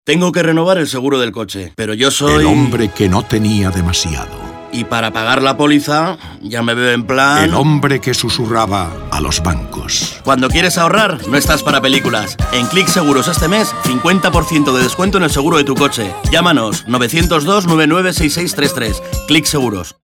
Se utilizaron cuñas de 20 segundos y menciones en directo realizadas por los conductores de los programas.
Audios de la campaña: